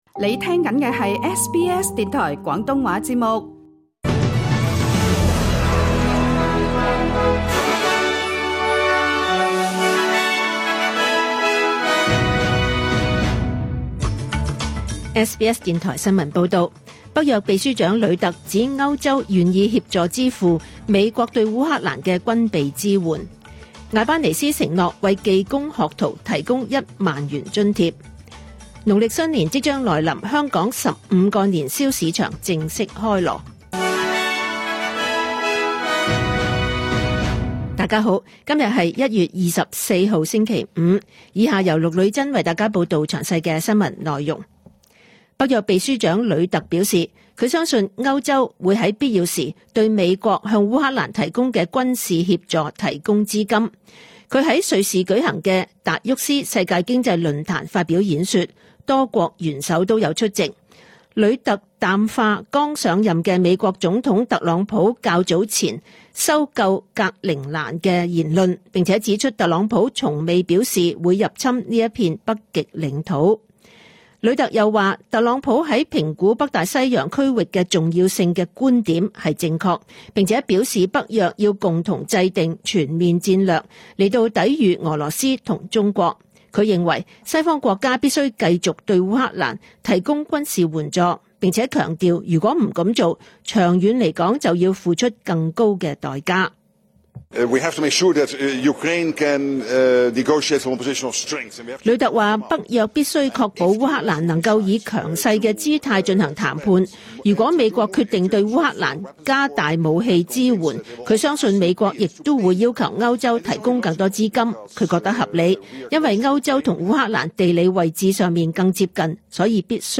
2025 年 1 月 24 日 SBS 廣東話節目詳盡早晨新聞報道。